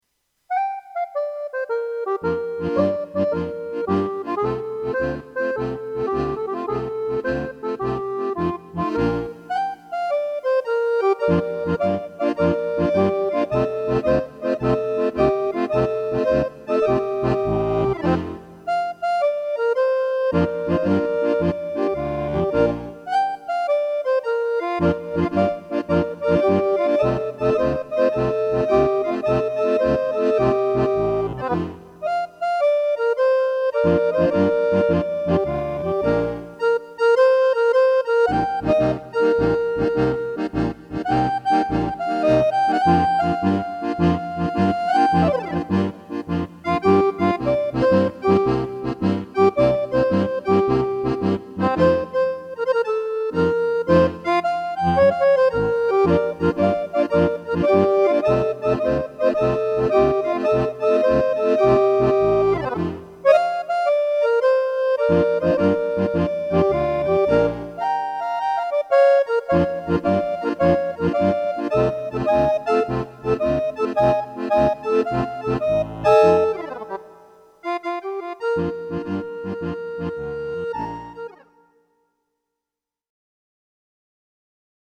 ne kleine Bass-MII-Etüde